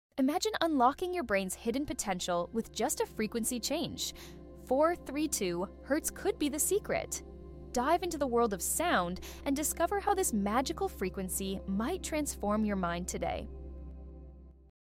🌿 432 Hz is the frequency of harmony — found in birdsong, ocean waves, and the wind.